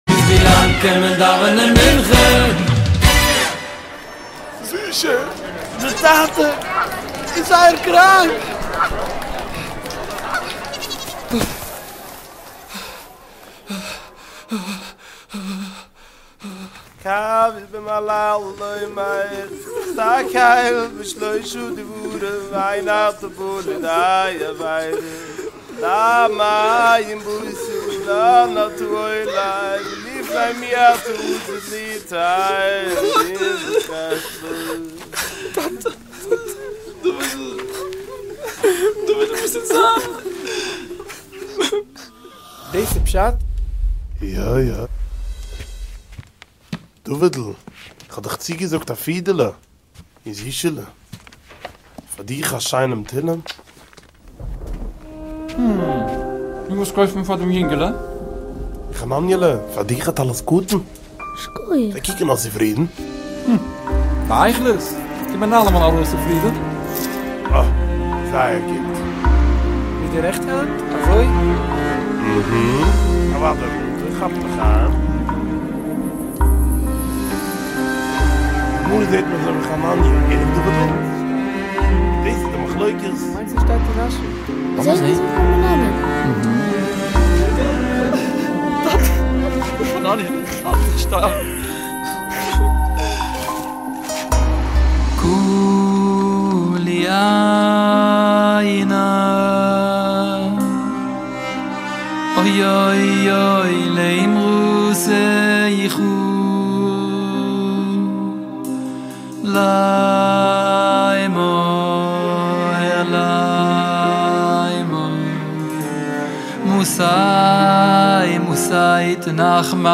ילד סולן
מקהלת ילדים